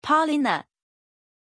Pronunciation of Paulina
pronunciation-paulina-zh.mp3